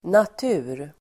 Uttal: [nat'u:r]